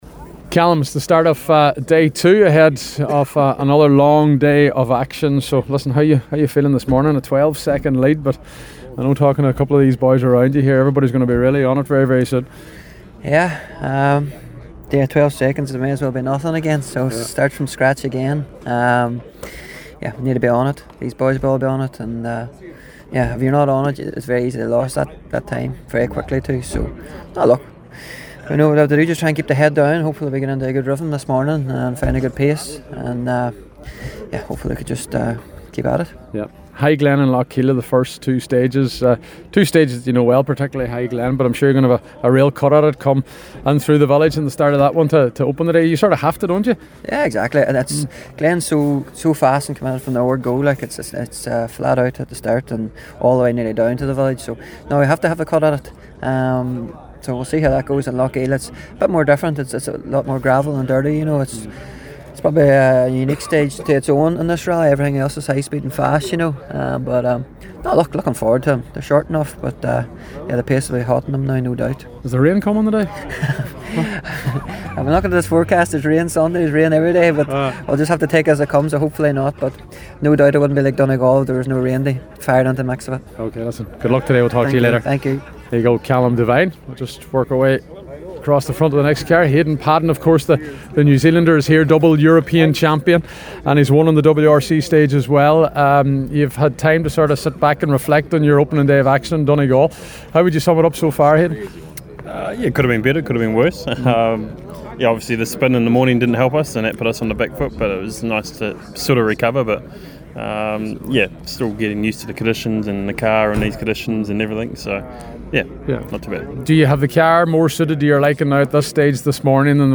we’ll hear from the top five in the international section